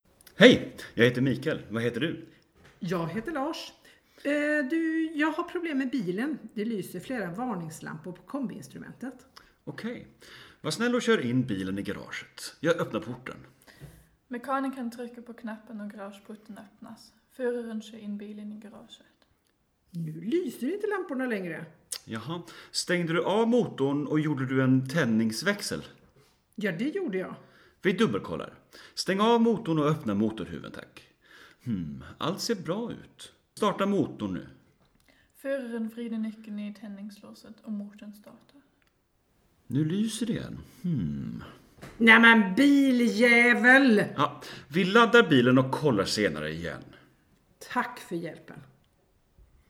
Soundfile Dialog (schwedisch):